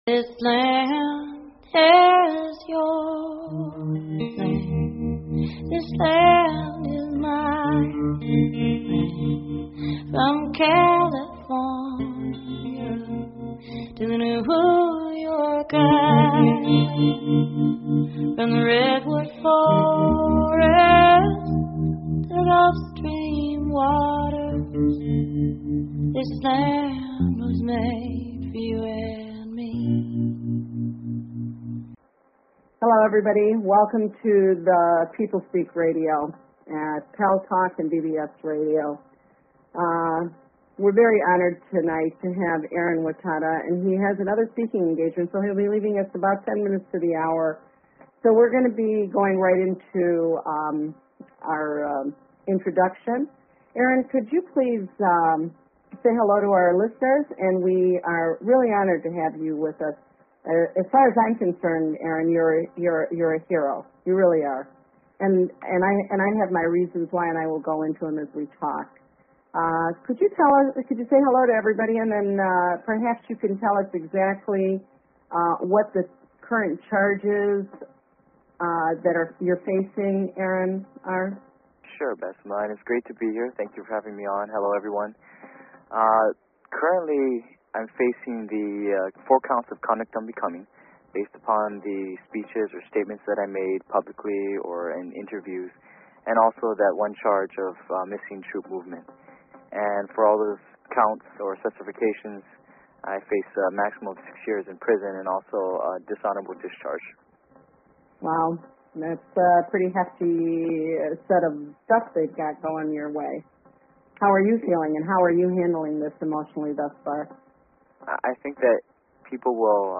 Talk Show Episode, Audio Podcast, The_People_Speak and Ehren Watada on , show guests , about , categorized as Politics & Government
Guest, Ehren Watada